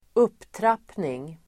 Ladda ner uttalet
Uttal: [²'up:trap:ning]
upptrappning.mp3